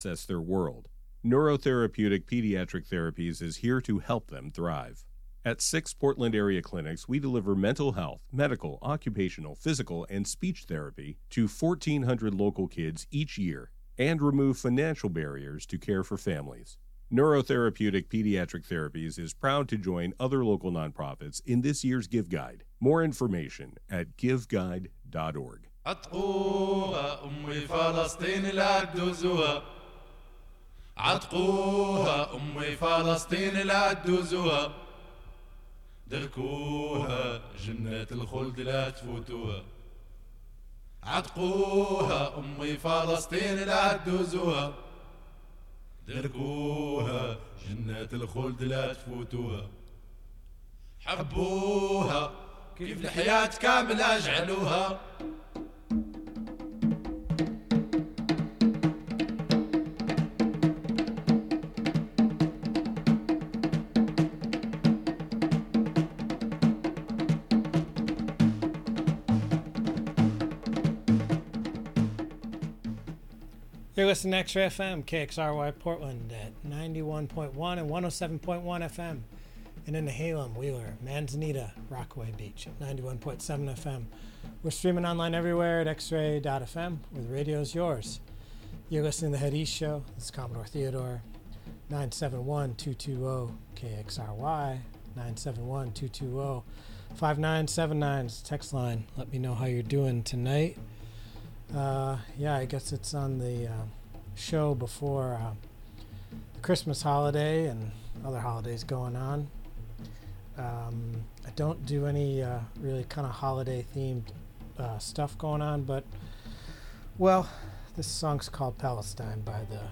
Drawing broadly from world interpretations of rock, soul, and punk, HEAD EAST brings you thee grooves, beats, riffs, and bleeps that matter to defining heaviness. Shaking up genres from the mid-60s thru the 80s with occasional newer jams, let's head east.